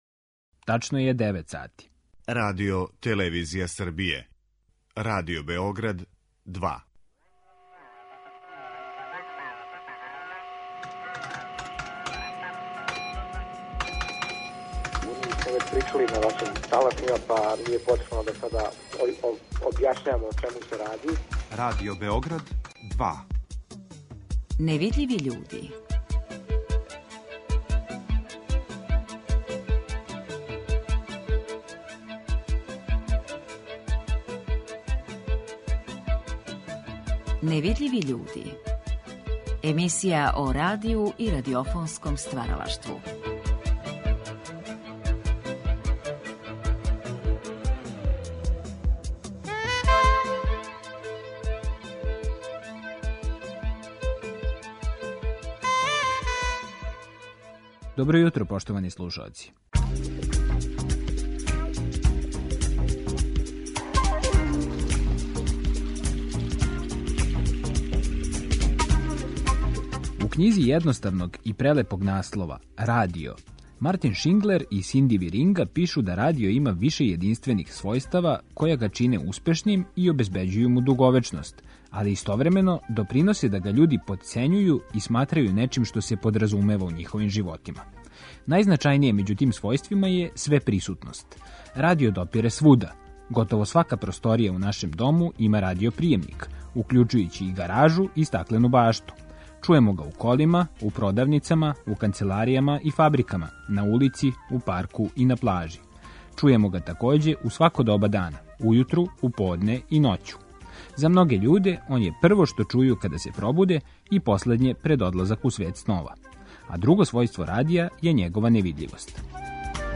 У данашњој емисији о радију и радиофонском стваралаштву разговарамо са Владаном Радовановићем, композитором, сликаром и мултимедијалним уметником, који је пре 45 година основао Електронски студио Радио Београда. Овај студио поставио је темеље у стварању нове уметничке електроакустичке и функционалне музике за потребе радија, телевизије и филма, а композиције настале у њему овенчане су признањима на водећим светским фестивалима електронског звука.
Емисија о радију и радиофонском стваралаштву.